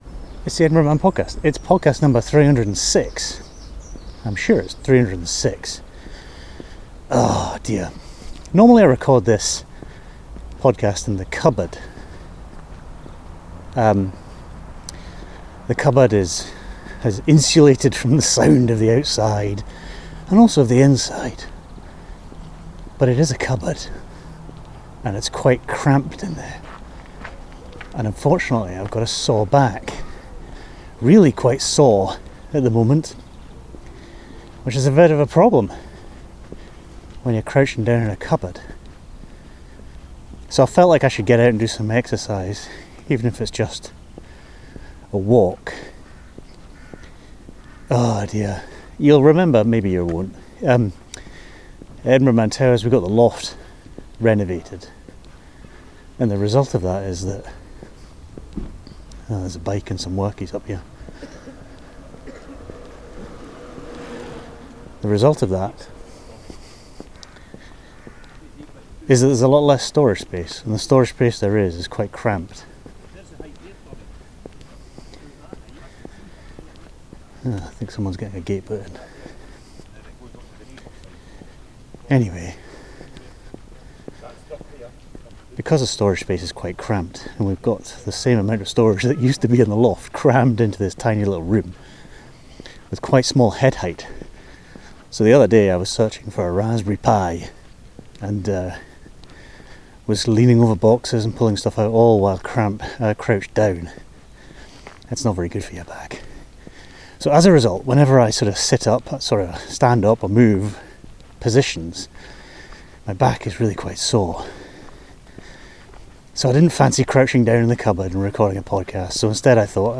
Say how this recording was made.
An indie music podcast. Made in Edinburgh in Scotland, but with podsafe music from all over the world.